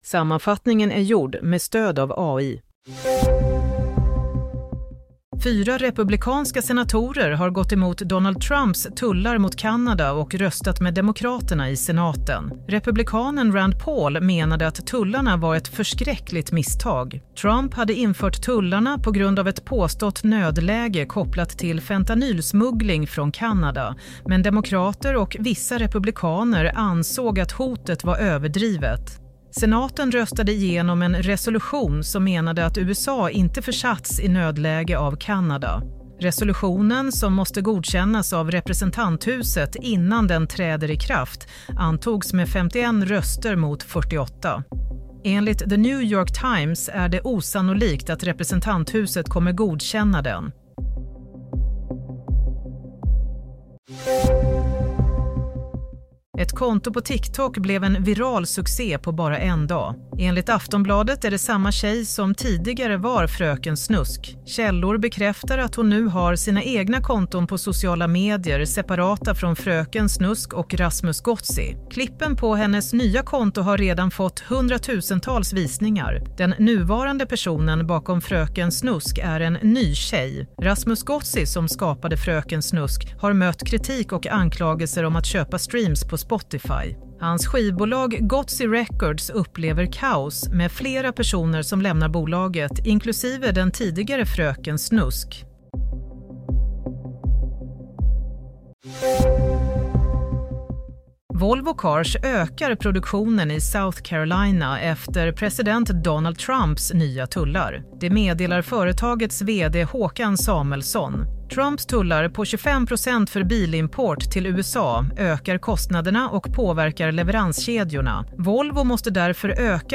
Nyhetssammanfattning - 3 april 16:00